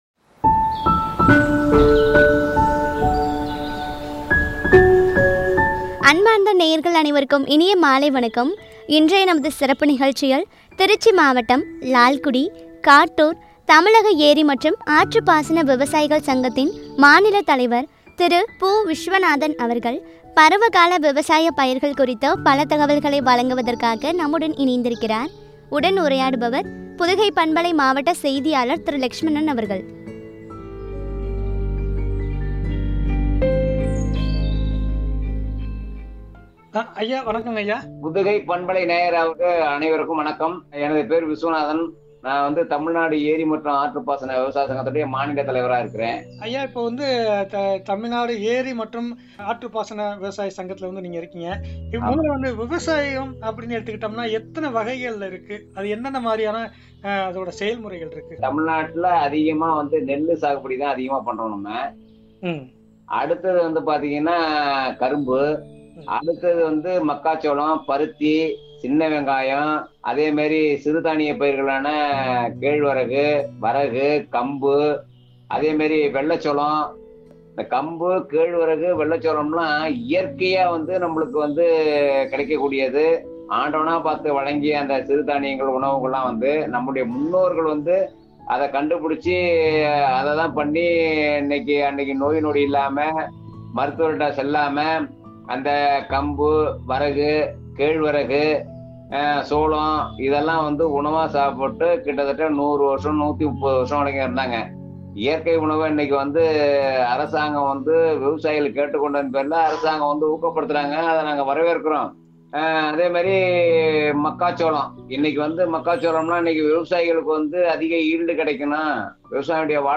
பருவகால விவசாய பயிர்கள் பற்றிய உரையாடல்.